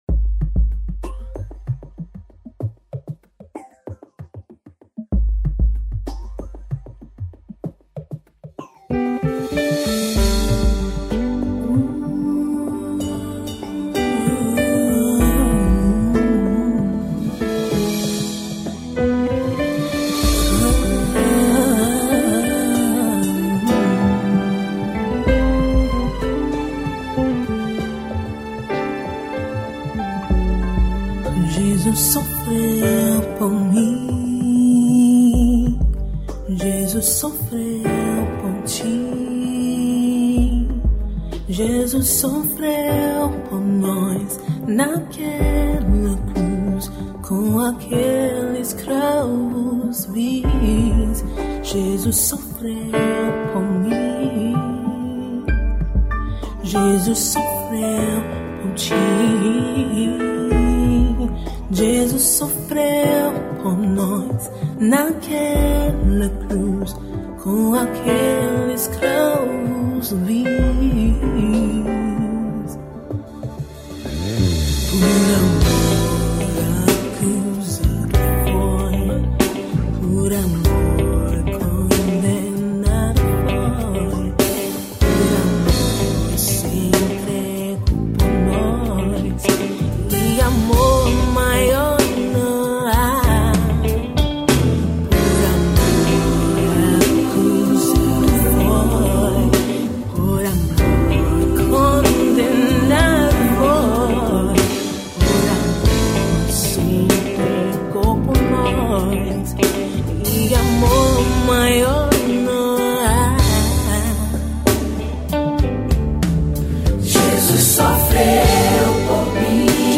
EstiloSoul